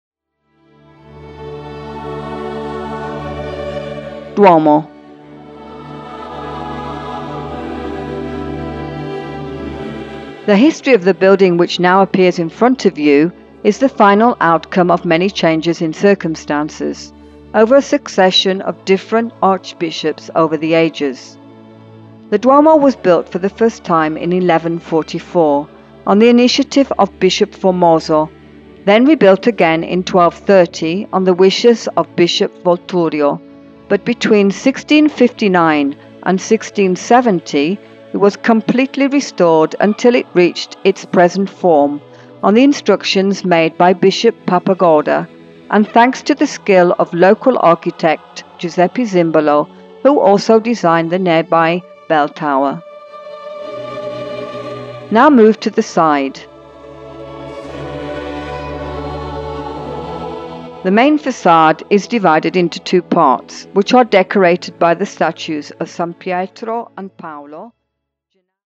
Happy Tourist è la nuova audio guida turistica digitale
Ascolta la DEMO di un commento di un Opera in Italiano, Inglese o Francese e scopri la bellezza delle descrizioni e la loro particolarità.